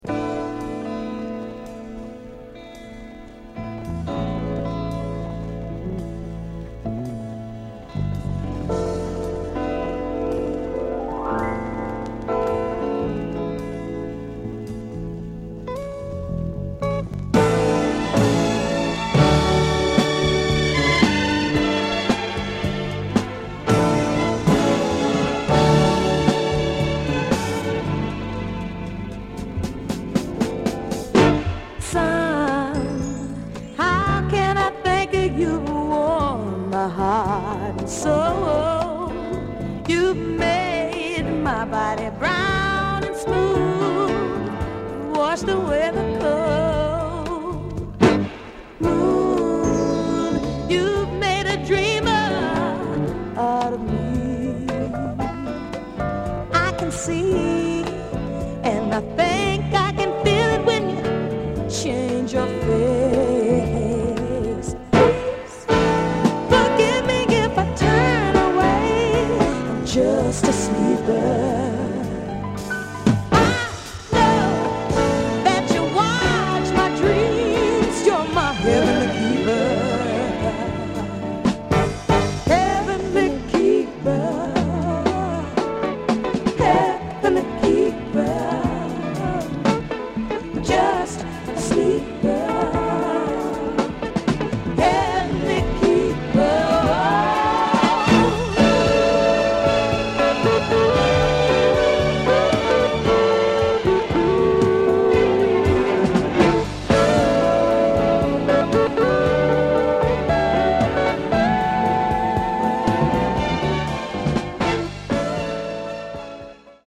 Club classic